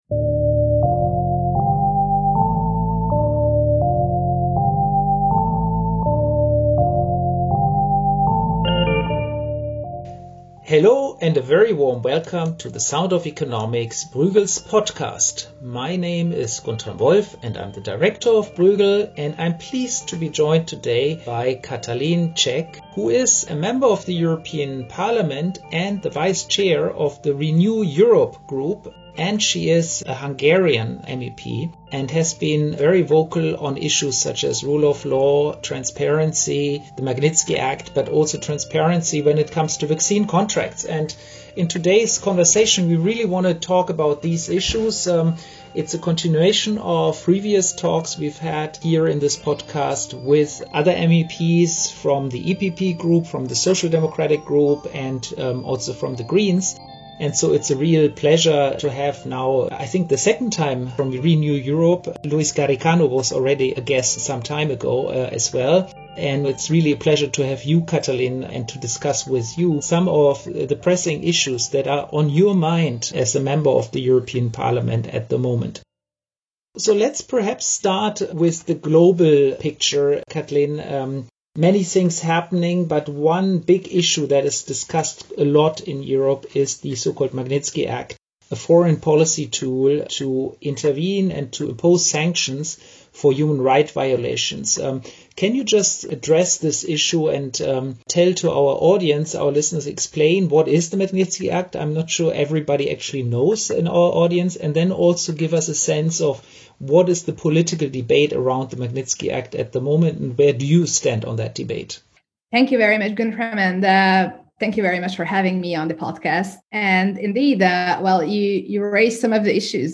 Transparency, human rights and good governance: a conversation with Katalin Cseh MEP